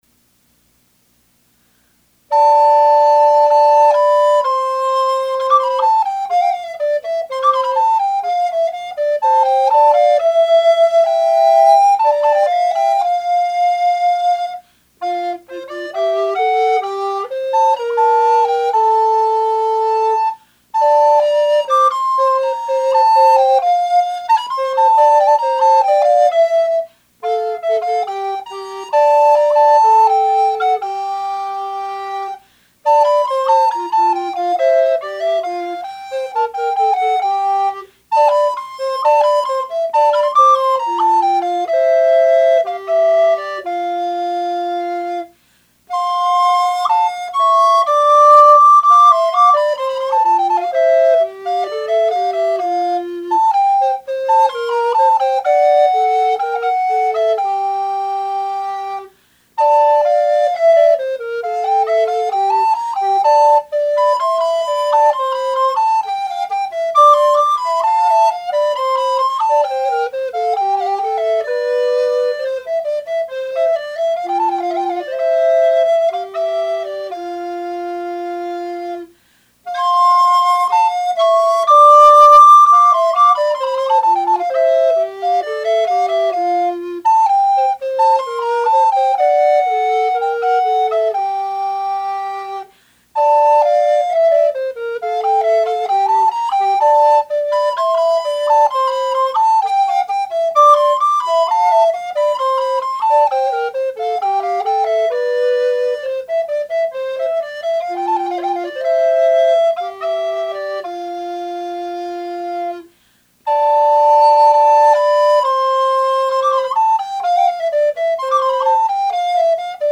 From the 5 November 2006 concert, La Caccia